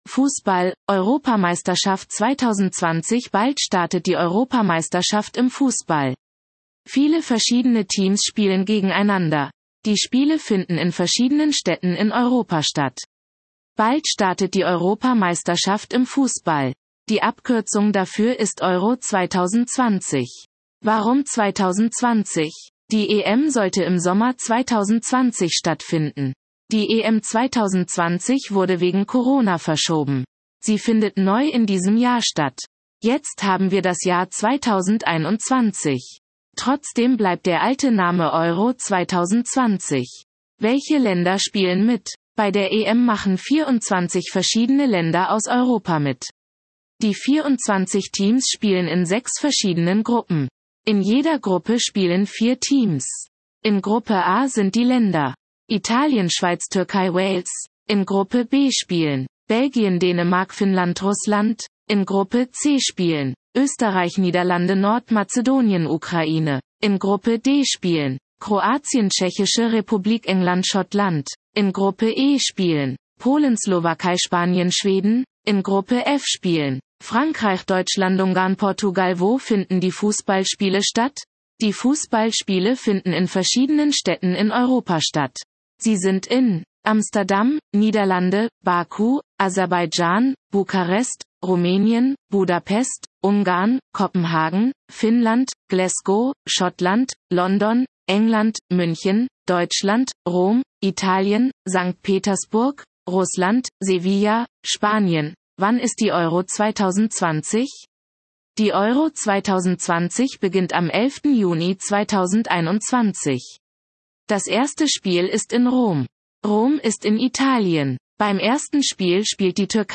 Vorlesen